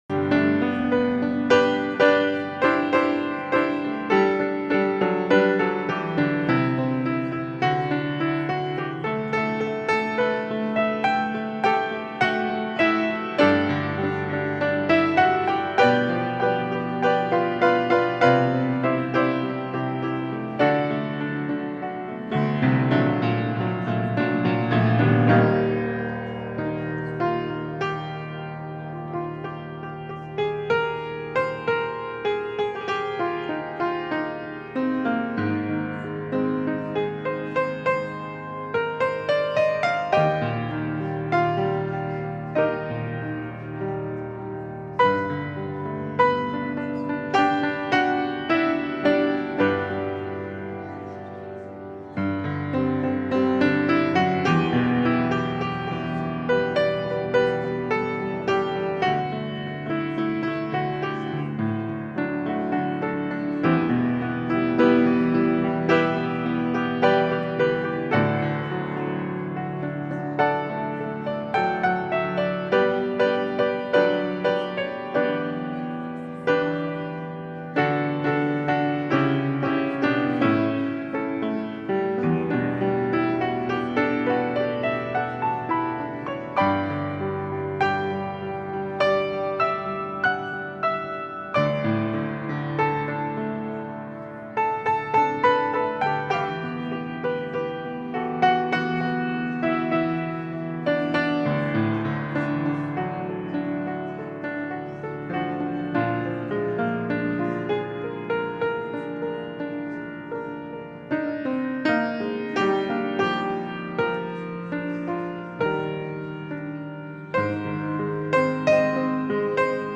Worship-March-9-2025-Voice-Only.mp3